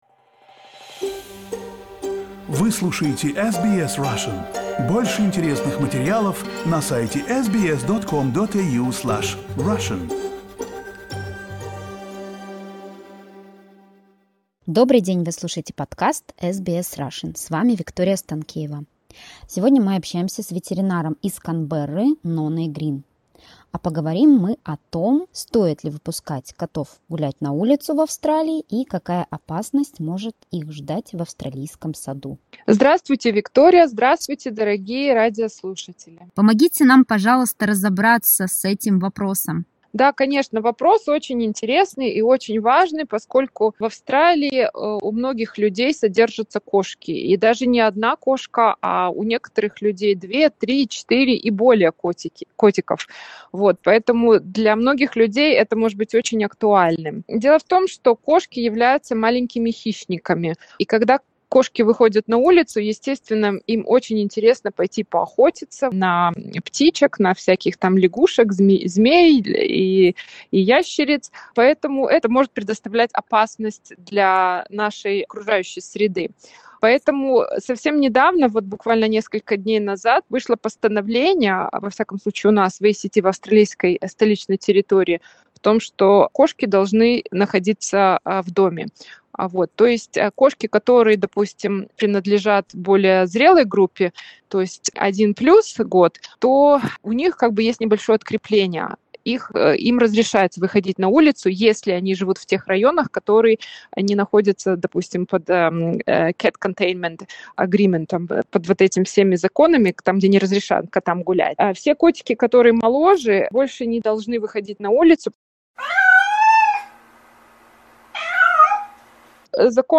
Interview with a veterinarian from Canberra